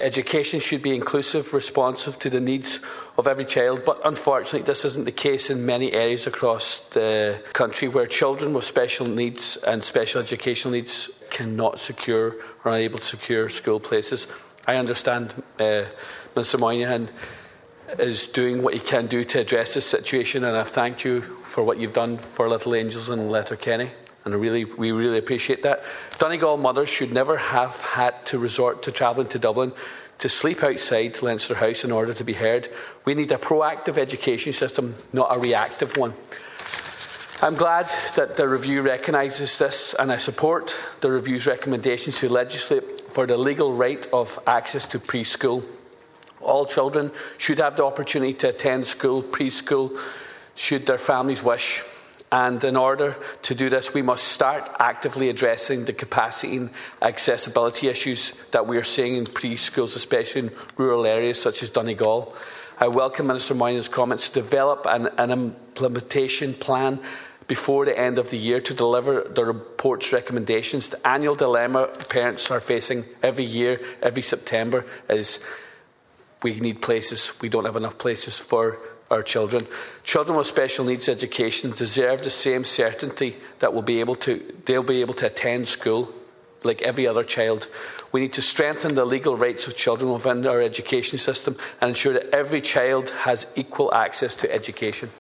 He was speaking during a Dail debate on a report which has just been published following a review of last year’s Education for People with Special Needs Act.